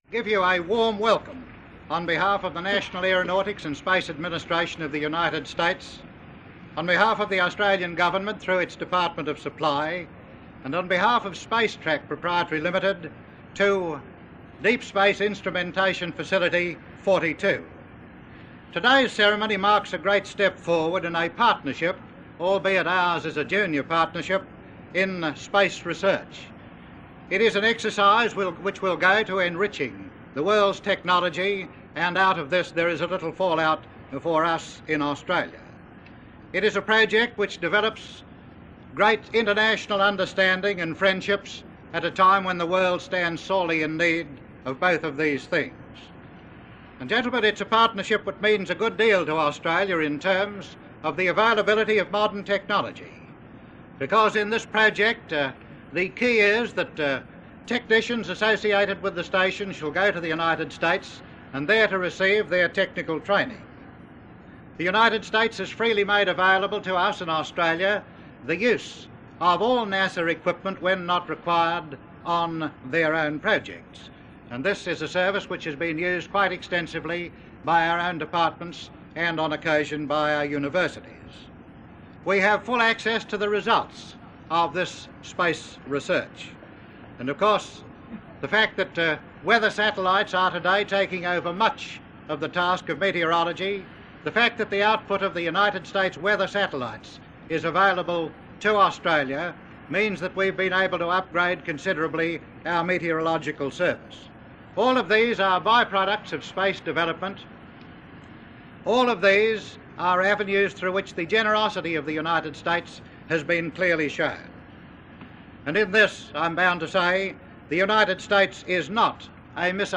Australian Minister for Supply Allen Fairhall.
Audio tape preserved in the National Archives of Australia.
02_Minister_for_Supply_Allen_Fairhall.mp3